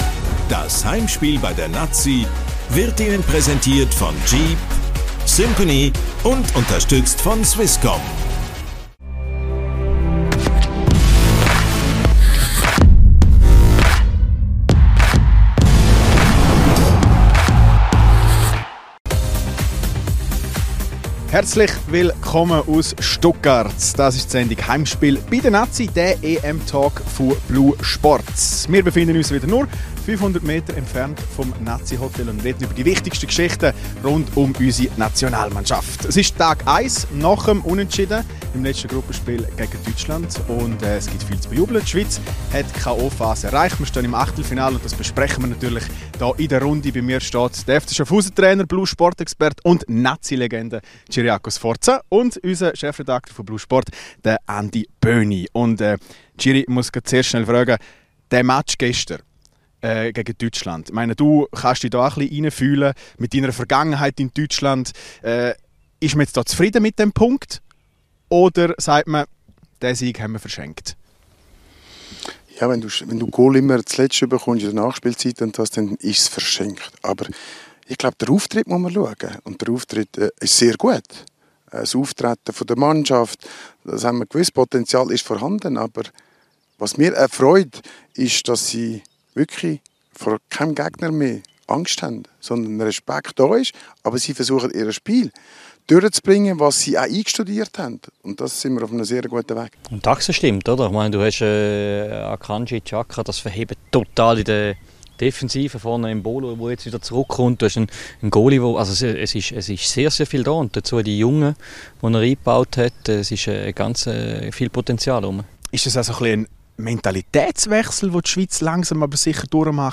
Heimspiel bei der Nati – der EM-Talk von blue Sport.